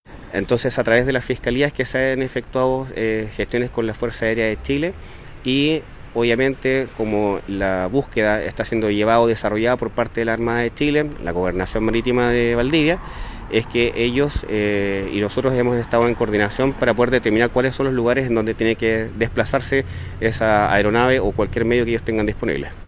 El capitán de puerto de Valdivia, Gonzalo Townsend, confirmó que la petición de dicha aeronave ya se hizo e incluso fueron compartidas las coordenadas del perímetro donde debería sobrevolar.